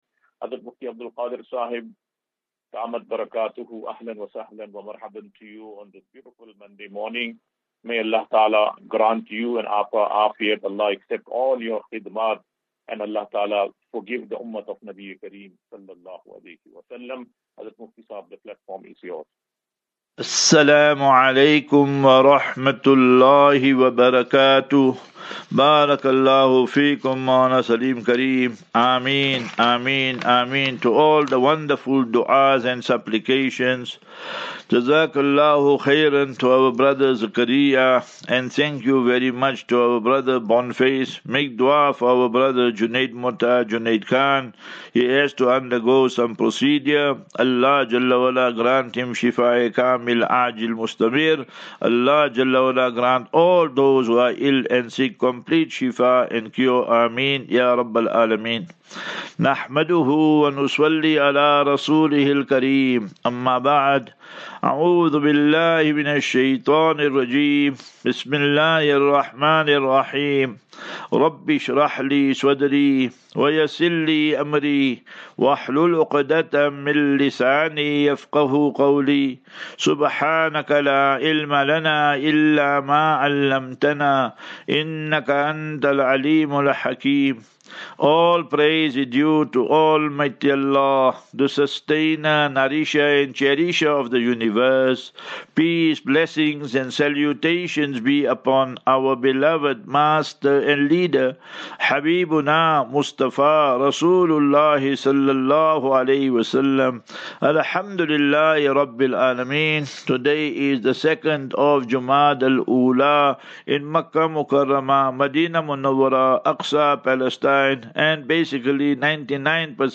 Daily Naseeha.